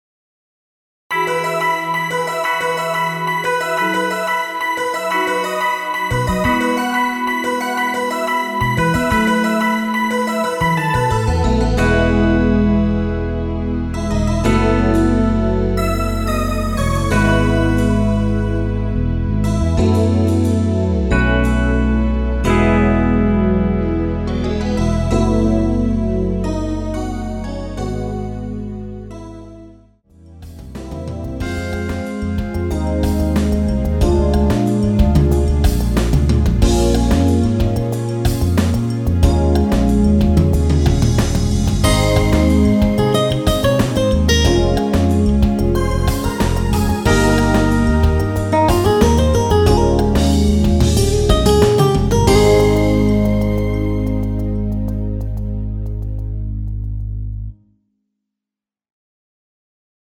엔딩이 페이드아웃이라 노래하기 편하게 엔딩부분을 다시 만들었습니다(미리듣기 참조하세요)
원키에서(-1)내린 MR입니다.
앞부분30초, 뒷부분30초씩 편집해서 올려 드리고 있습니다.